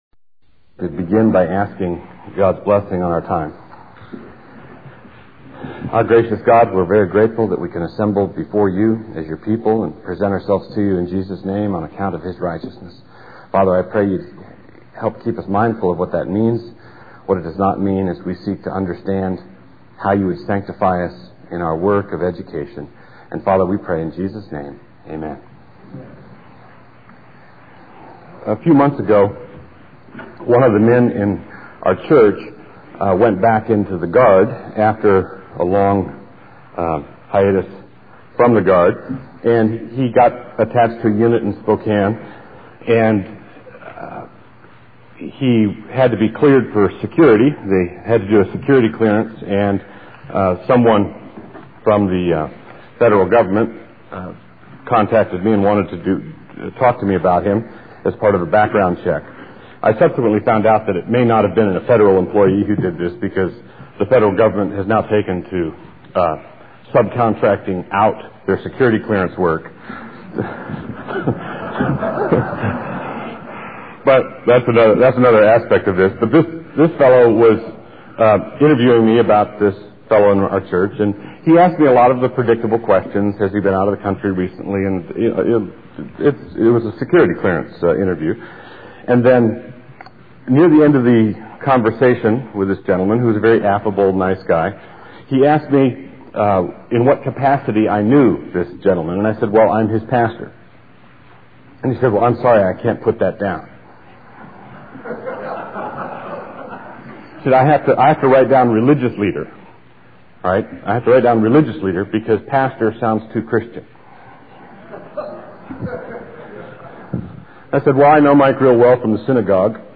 2002 Workshop Talk | 1:00:18 | All Grade Levels, Culture & Faith
He is the author of numerous books on classical Christian education, the family, and the Reformed faith Additional Materials The Association of Classical & Christian Schools presents Repairing the Ruins, the ACCS annual conference, copyright ACCS.